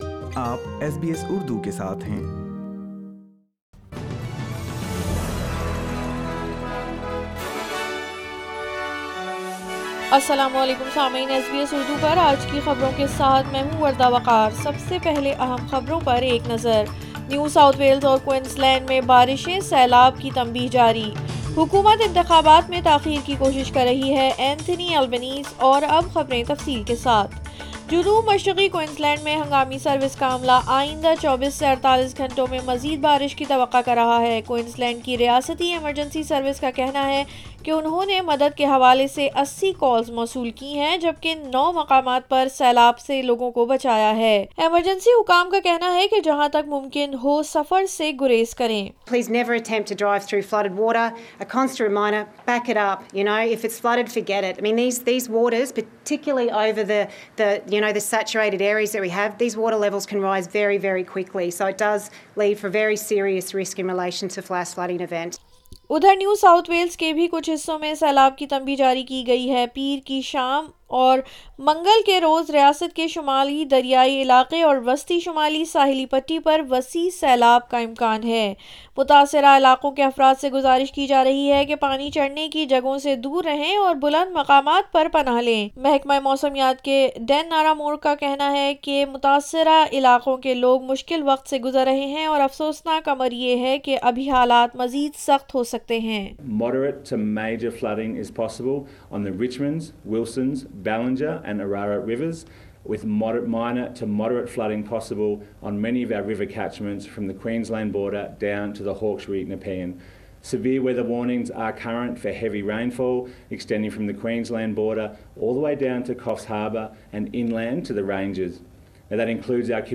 SBS Urdu News 28 March 2022